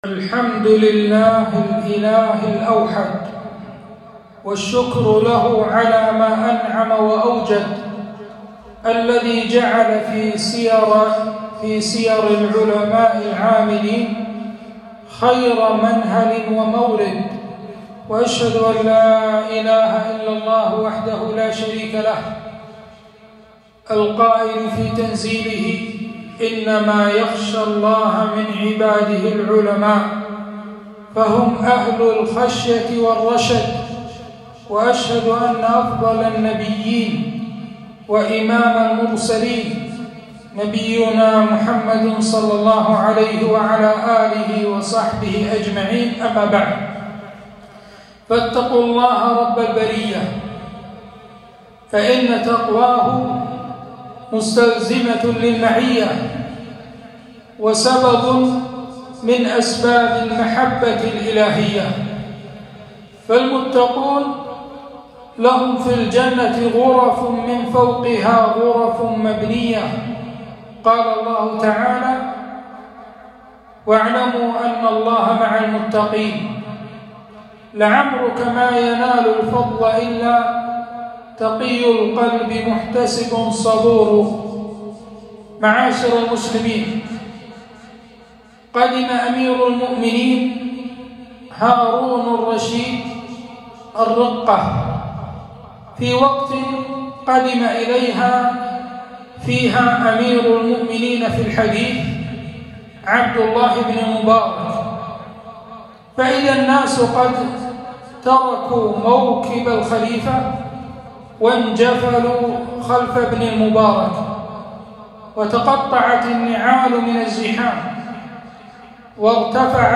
خطبة - معالم من سيرة الإمام سفيان الثوري - دروس الكويت